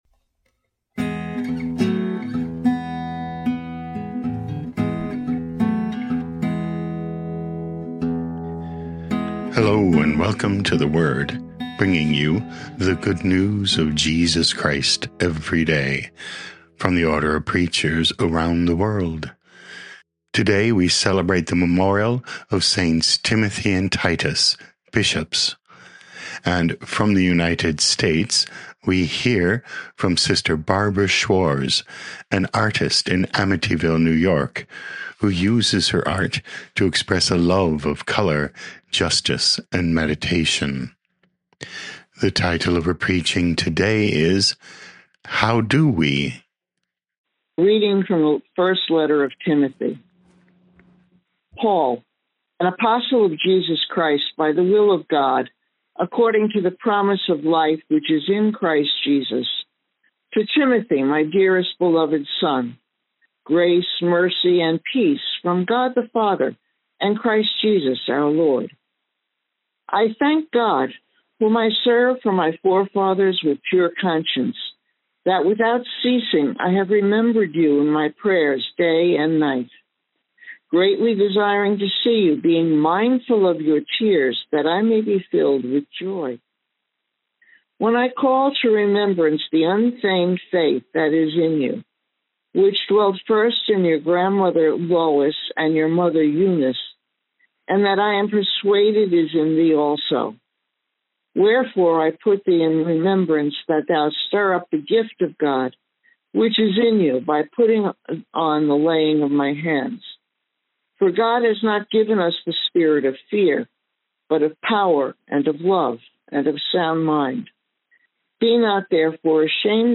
Podcast: Play in new window | Download For 26 January 2026, The Memorial of Saints Timothy and Titus, Bishops, based on 2 Timothy 1:1-8, sent in from Amityville, New York, USA.
Preaching